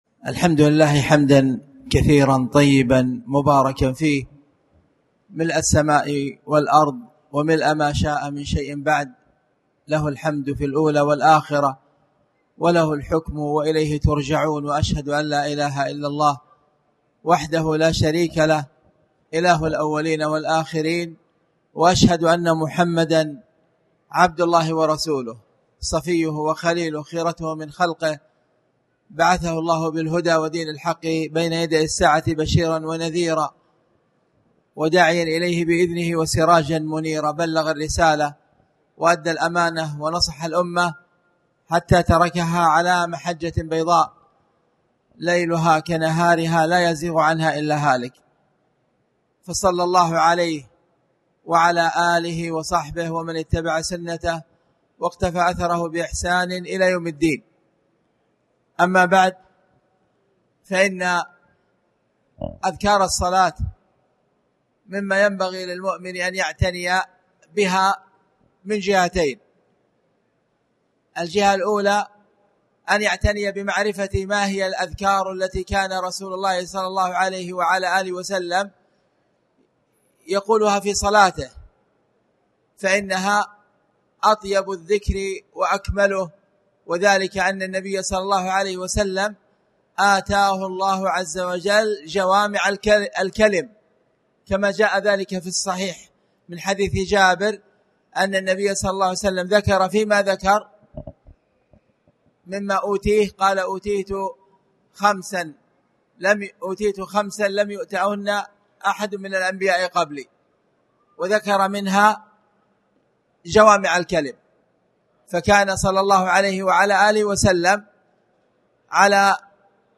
تاريخ النشر ١٧ محرم ١٤٣٩ هـ المكان: المسجد الحرام الشيخ